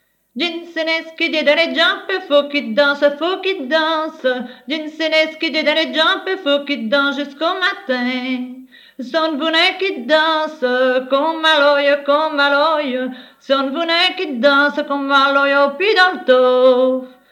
Genre : chant
Type : chanson narrative ou de divertissement
Lieu d'enregistrement : Morville
Support : bande magnétique